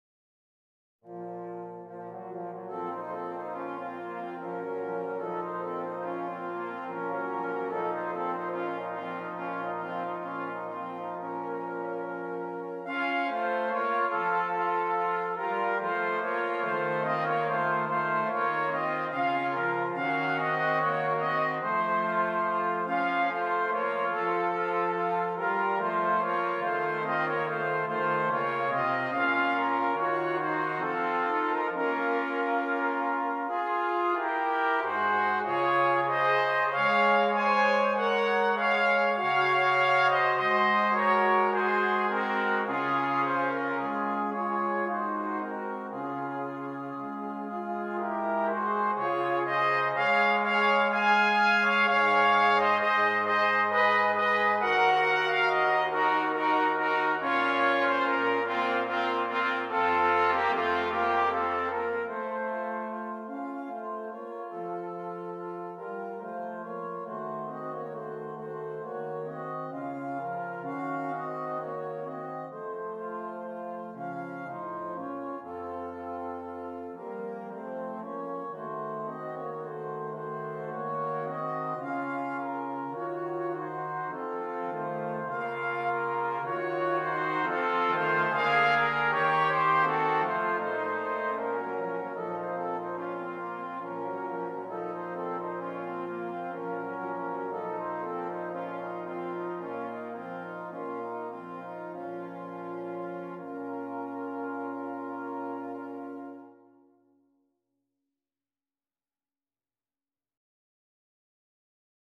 Brass Quartet
This particular piece is rustic in character.
Alternate parts: Trombone for Horn